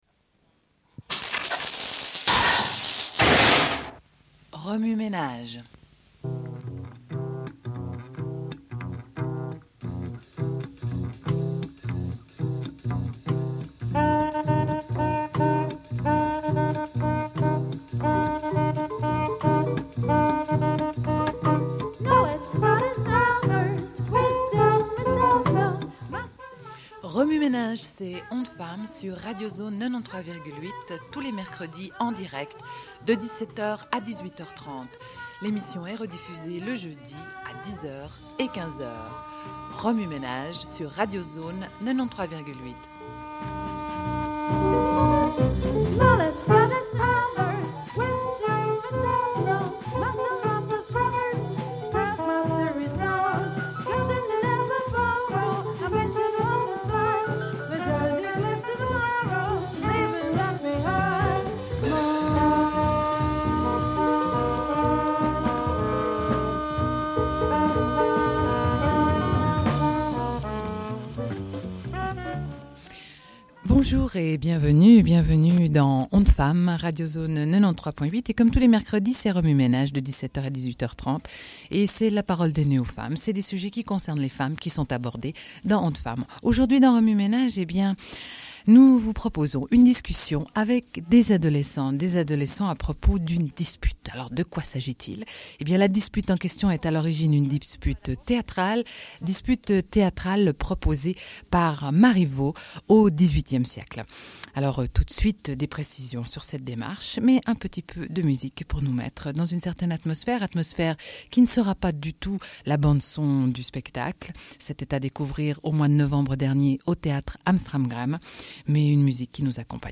Une cassette audio, face A31:25
Sommaire de l'émission : rediffusion d'une discussion avec des adolescentes à propos de la pièce La Dispute de Marivaux, montée au Théâtre Am Stram Gram.